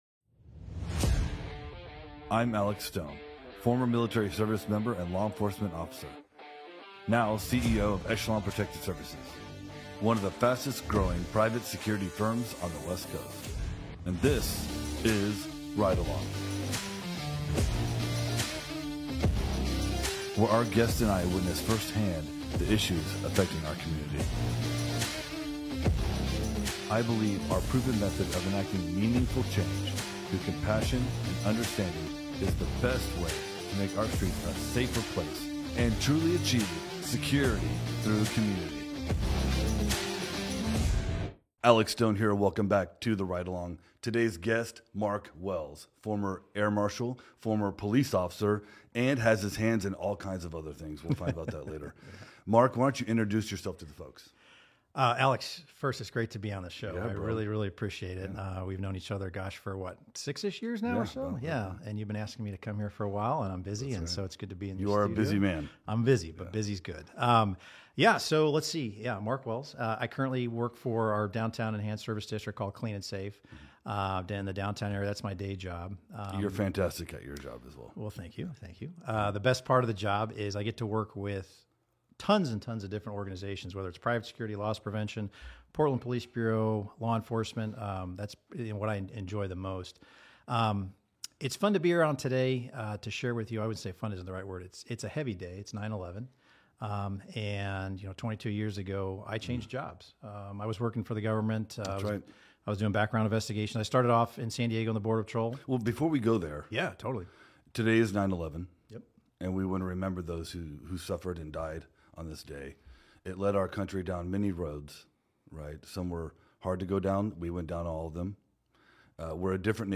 Challenges and Changes in Law Enforcement: A Conversation